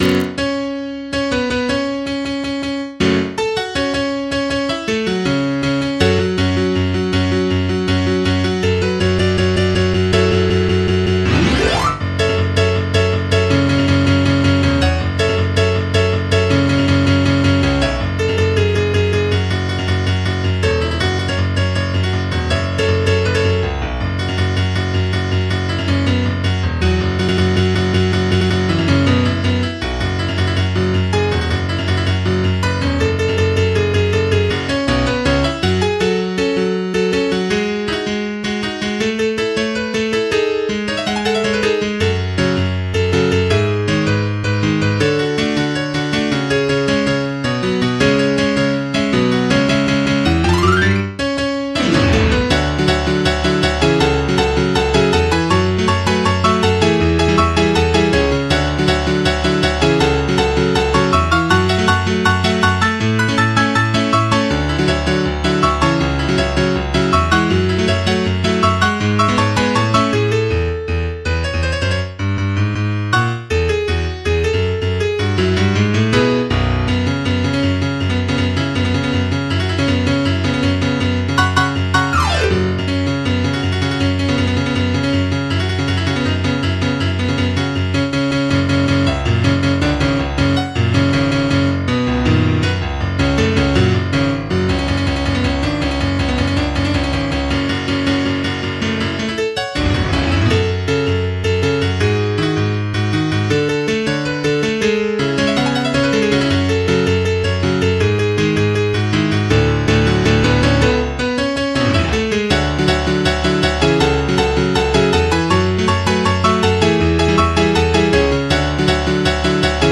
MIDI 26.12 KB MP3 (Converted) 3.55 MB MIDI-XML Sheet Music